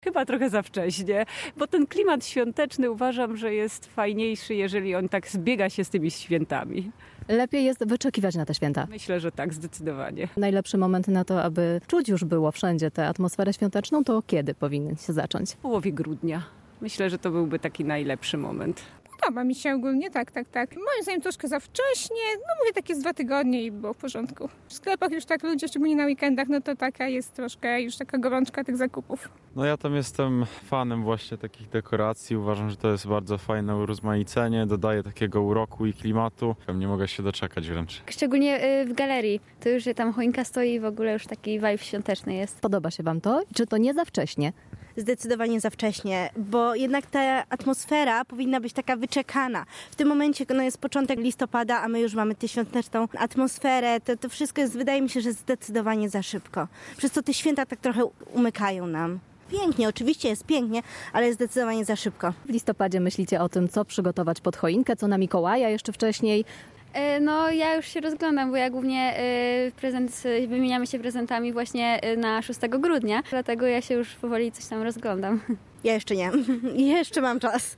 SONDA.mp3